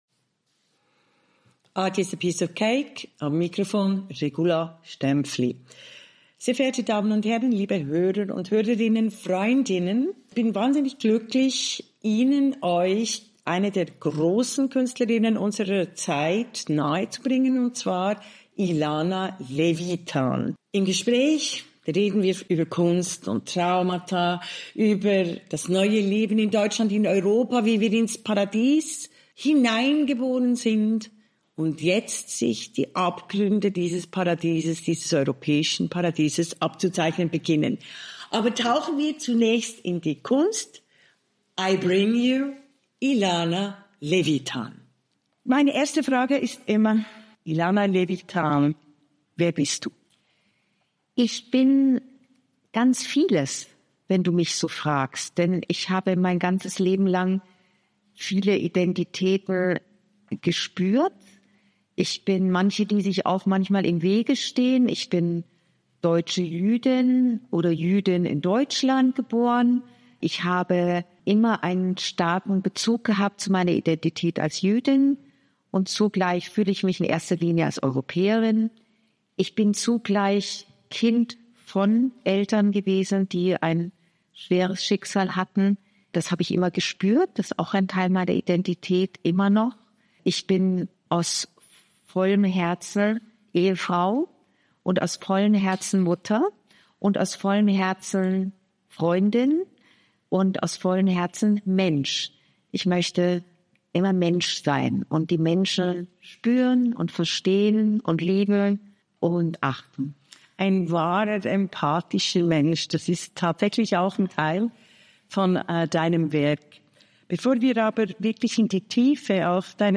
"Erinnerung hat Farbe." Über die Kunst, aus der Stille zu sprechen. Ein Gespräch
in ihrem Atelier